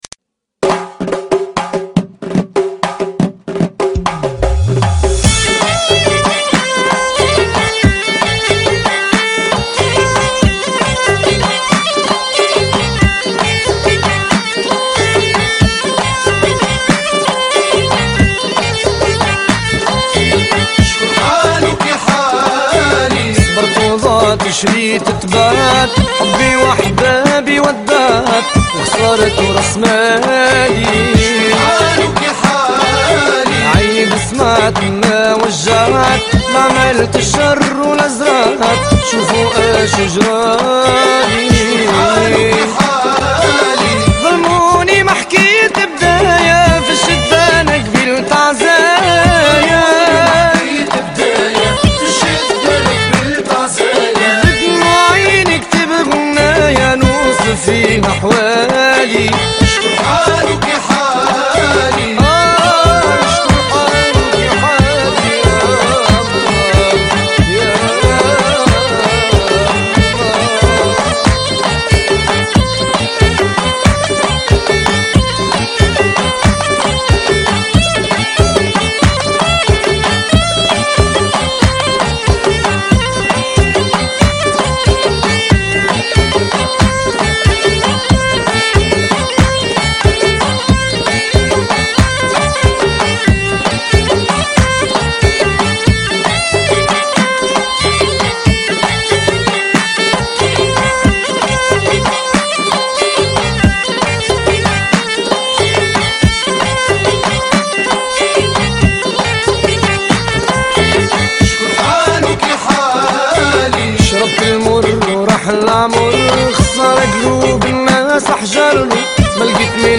Bienvenue au site des amateurs de Mezoued Tunisien